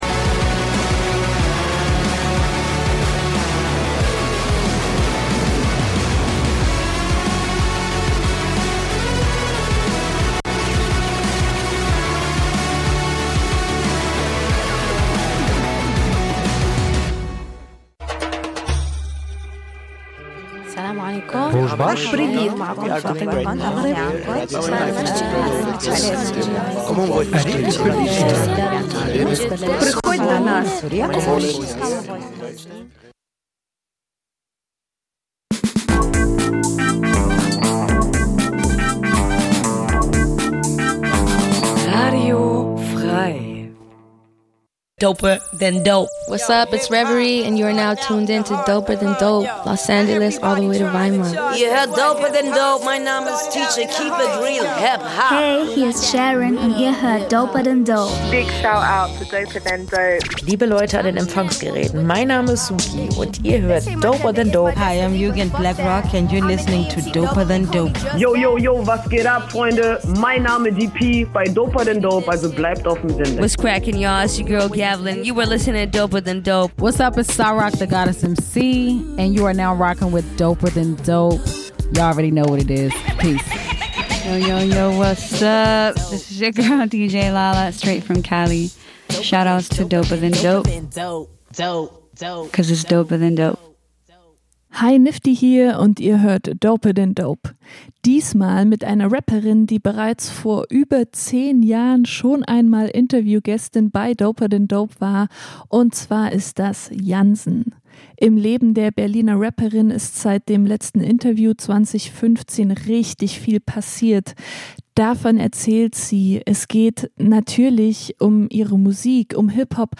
Bei doper than dope wird HipHop-Kultur gefeiert! Dabei werden auch Entwicklungen und wichtige Releases im HipHop Mainstream angeschaut, der Fokus liegt aber vor allem auf Sub-Szenen und Artists, die oft zu wenig Beachtung finden, wie Frauen und queere Rapper*innen.